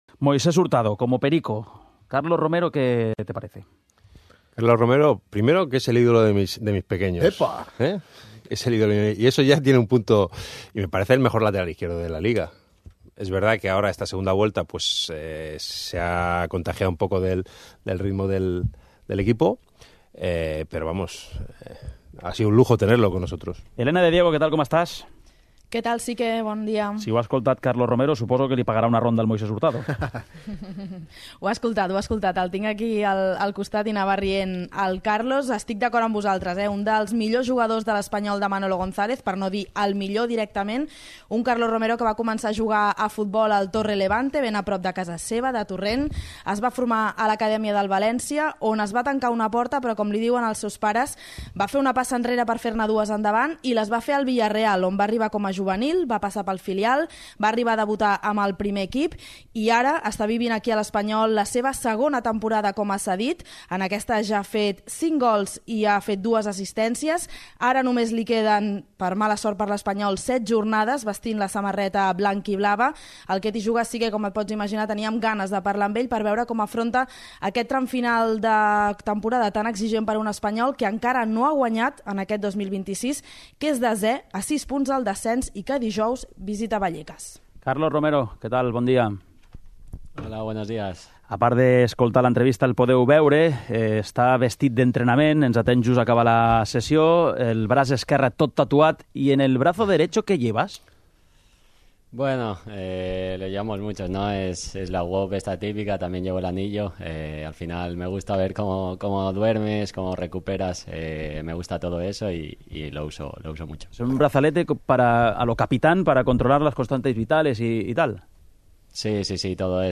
Al 'Què t'hi Jugues!' entrevistem a Carlos Romero, jugador de l'Espanyol cedit pel Villareal